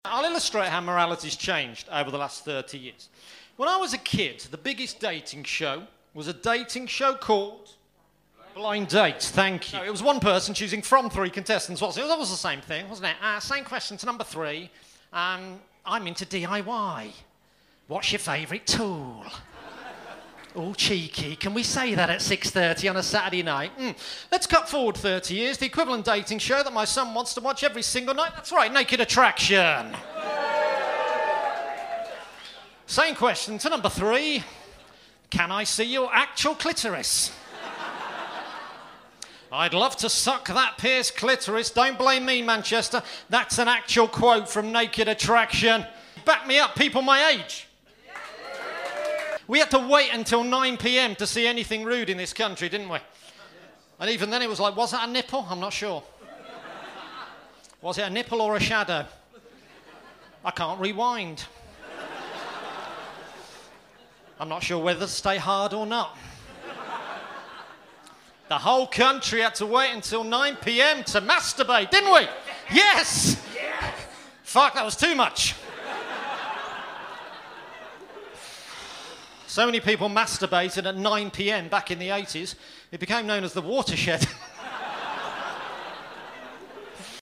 Recorded at The Frog and Bucket Comedy Club, Manchester